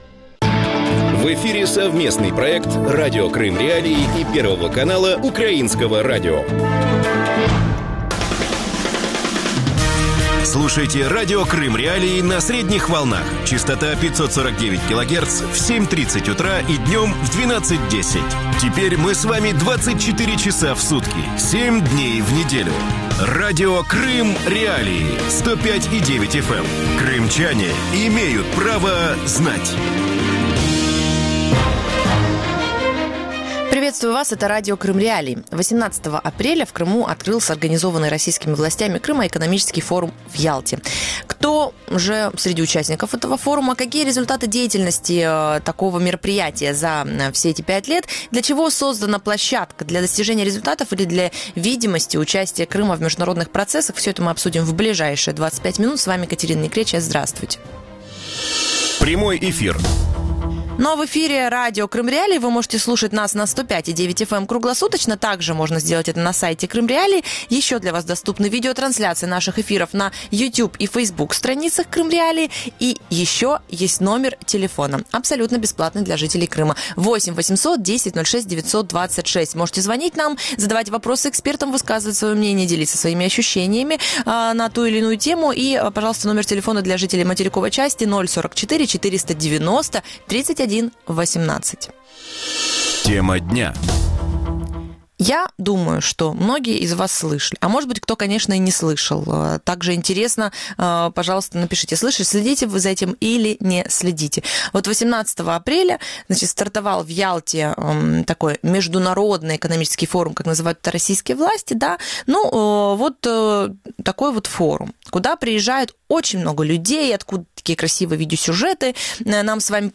Для чего создана такая площадка: для достижения результатов или для видимости участия Крыма в международных процессах? Гости эфира